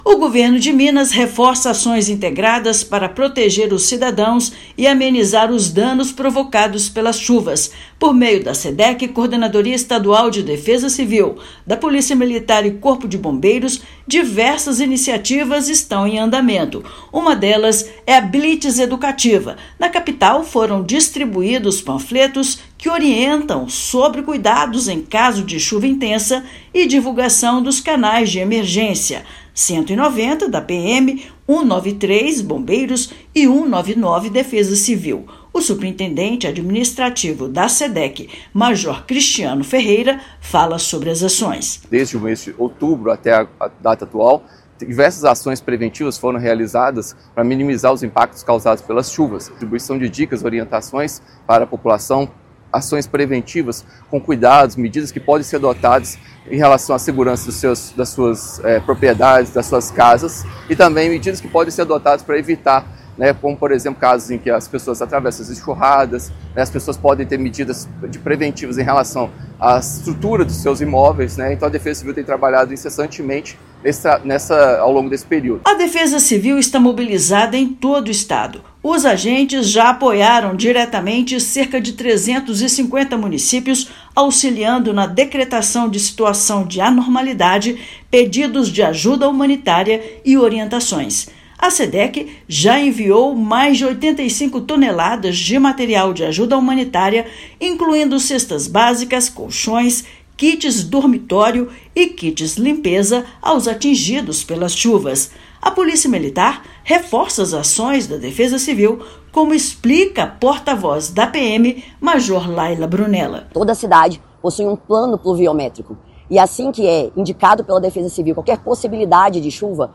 Defesa Civil, Polícia Militar e Corpo de Bombeiros promovem blitz educativa para explicar o trabalho conjunto para reduzir os impactos do período chuvoso e alertar a população sobre os canais para solicitar ajuda em caso de emergência. Ouça matéria de rádio.